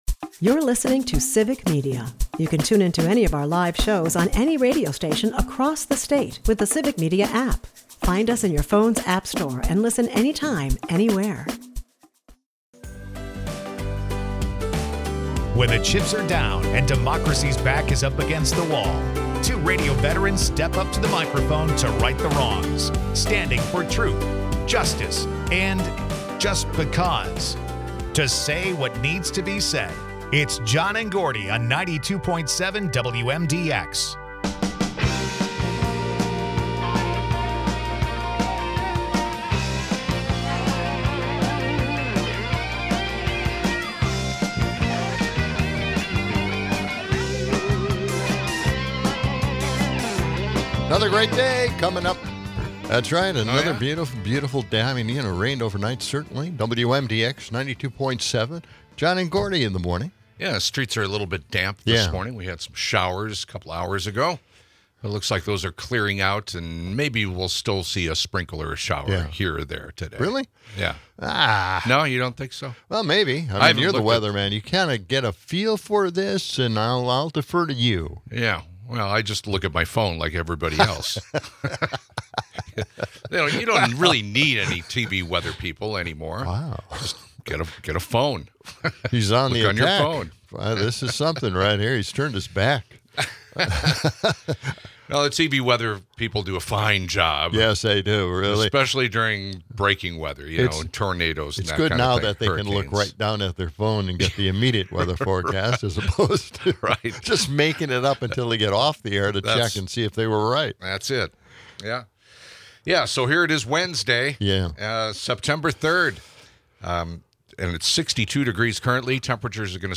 A call-in discussion questions the future impact of the MAGA movement.